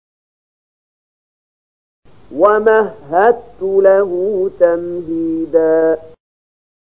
074014 Surat Al-Muddatstsir ayat 14 bacaan murattal ayat oleh Syaikh Mahmud Khalilil Hushariy: